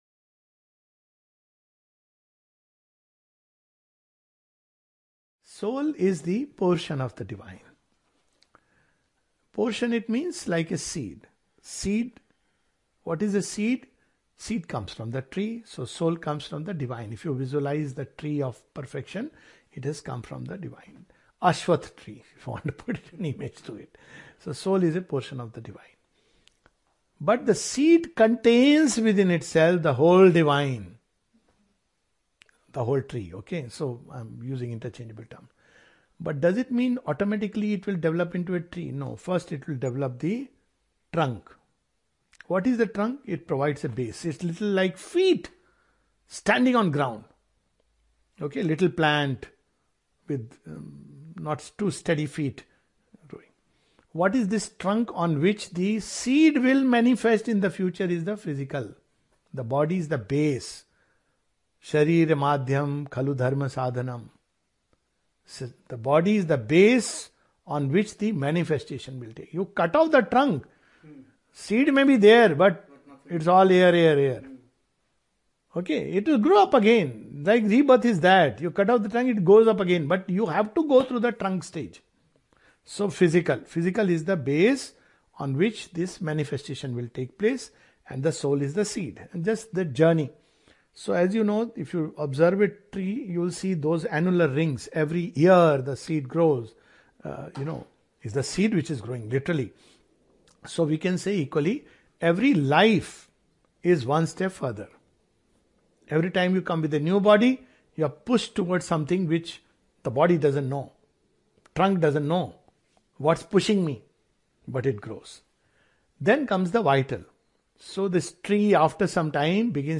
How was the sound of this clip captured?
(Sydney, Australia, Sept' 2024)